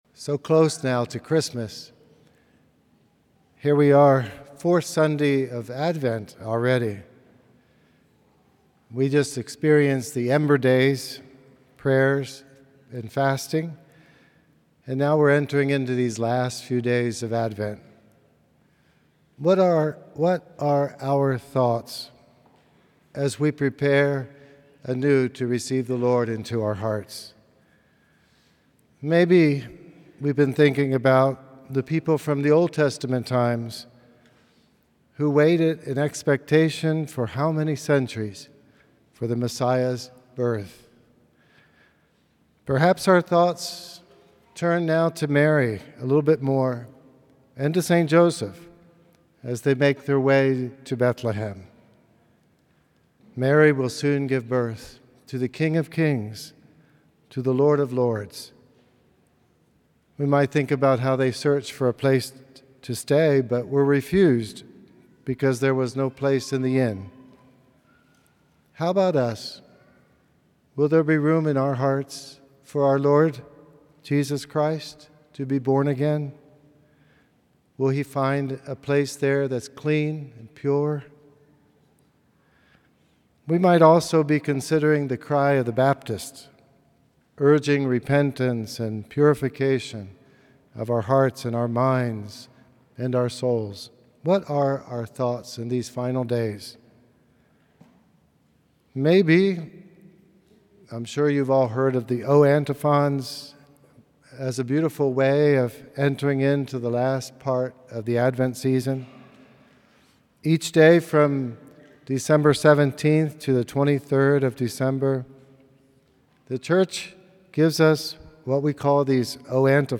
From Series: "Homilies"